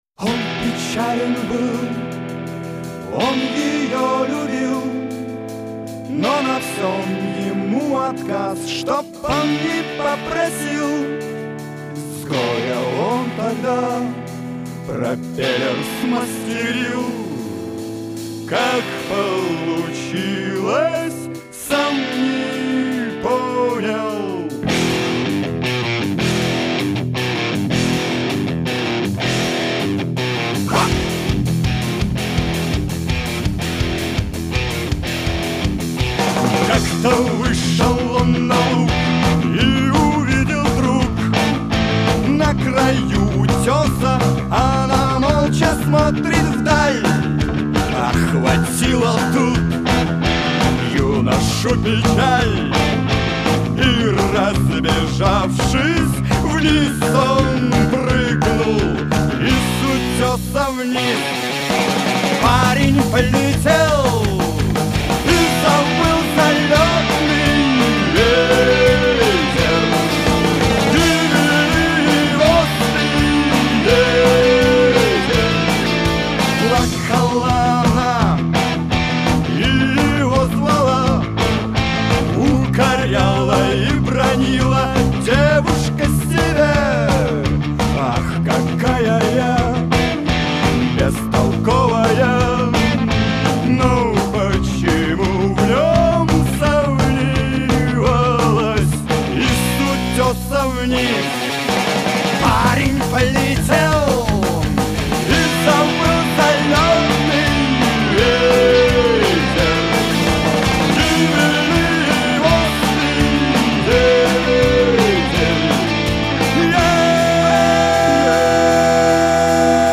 Рок [115]